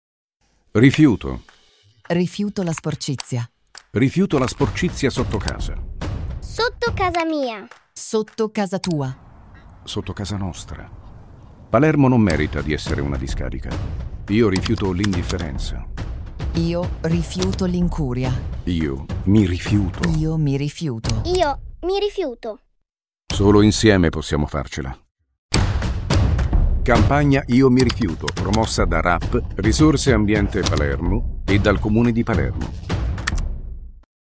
spot audio su emittenti radiofoniche locali
Spot-Radio_GB22.mp3